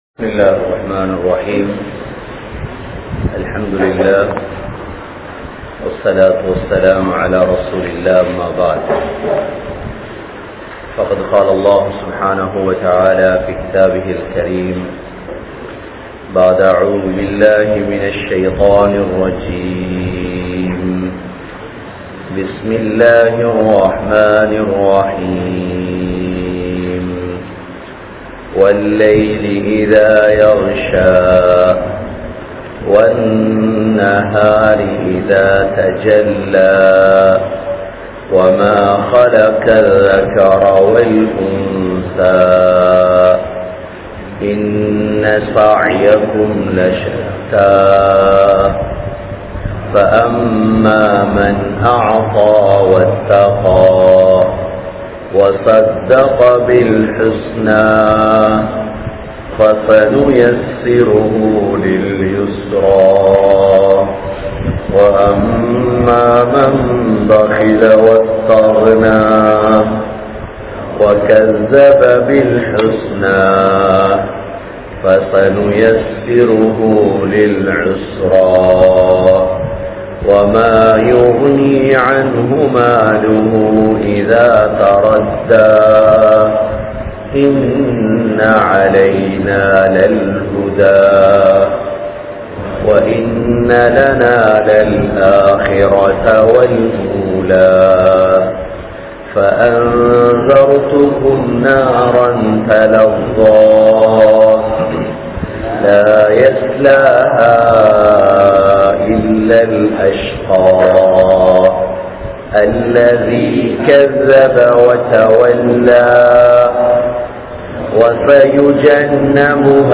NarahaVaathihal(Surah Lail Part 02) (நரகவாதிகள்) | Audio Bayans | All Ceylon Muslim Youth Community | Addalaichenai